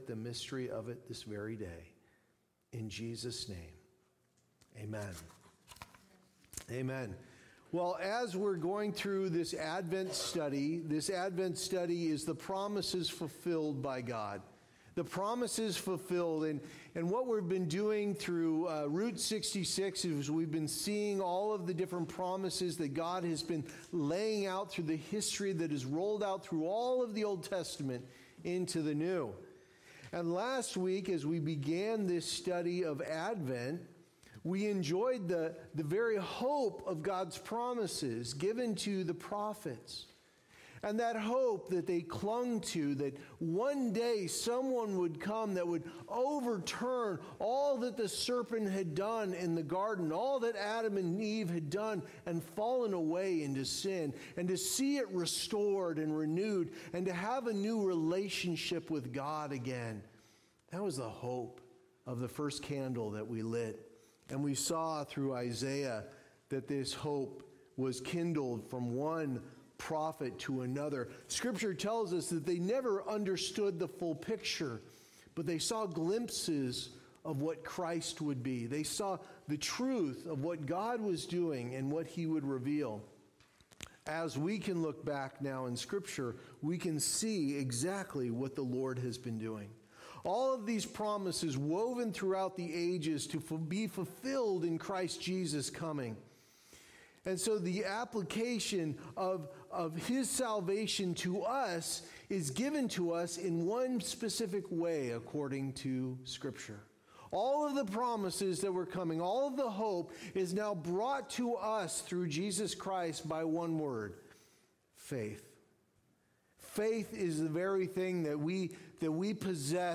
Passage: Micah 5:2, Luke 2:1-7, Hebrews 11:1 Services: Sunday Morning Service